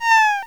falling.wav